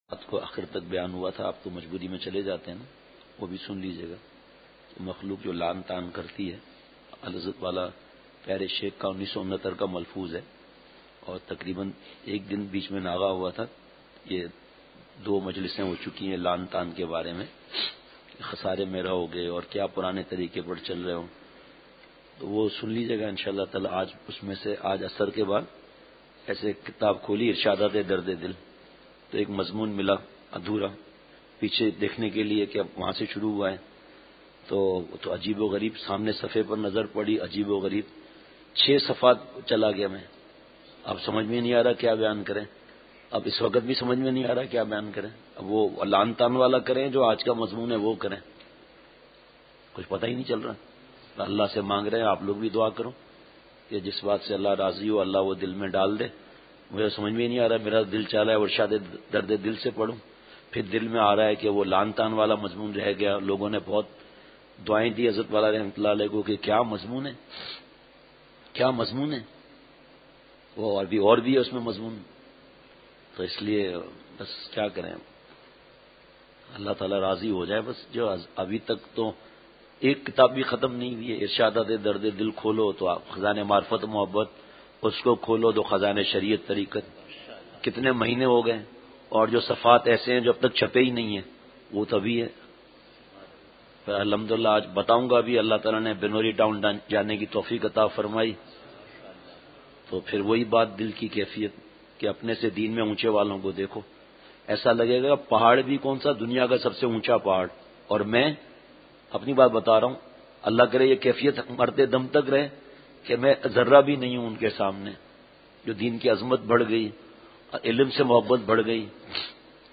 Safar Bayan 20 Feb 2019 Bd Fajr Haram Maal Ka Wabal Jamia Masjid Station Road Mpk